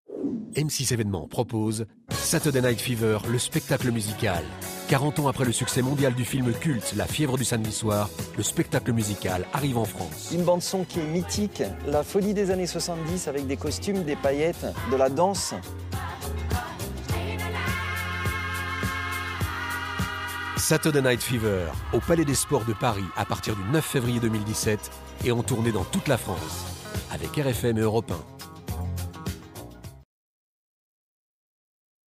Genre : Voix-off
M6-Promo-Saturday-night-fever-M6.mp3